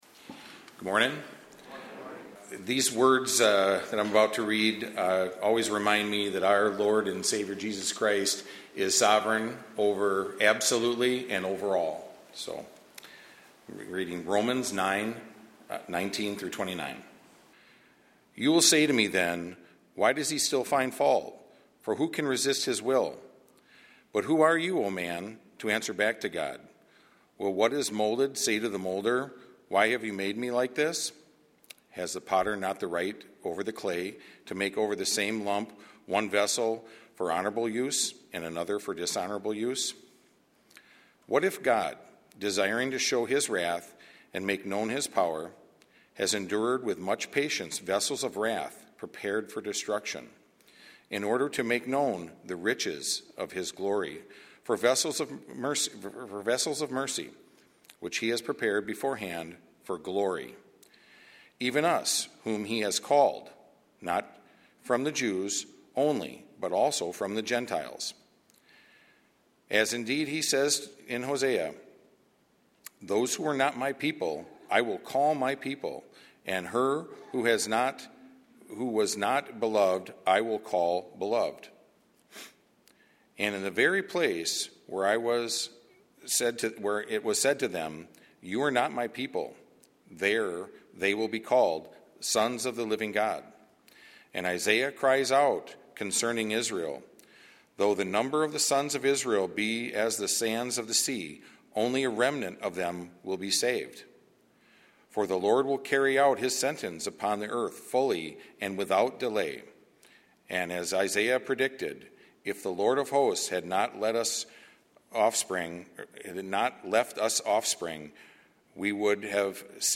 A sermon from the series "Romans."